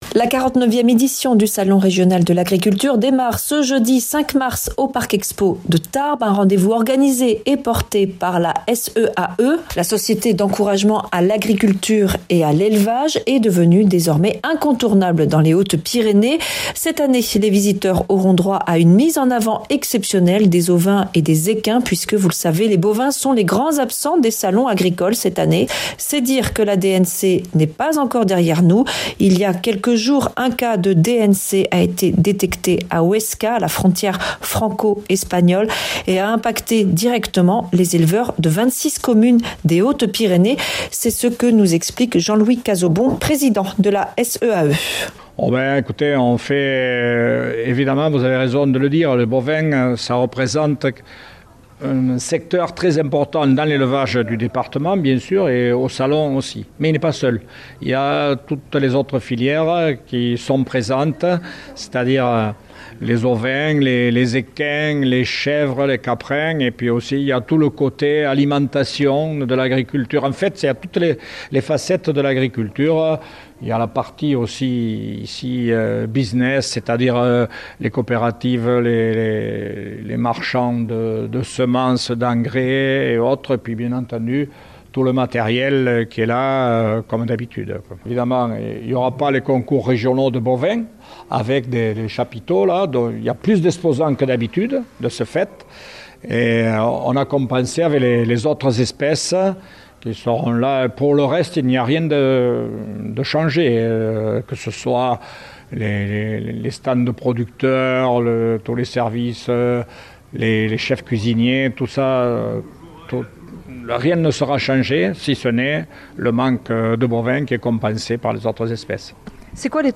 jeudi 5 mars 2026 Interview et reportage Durée 10 min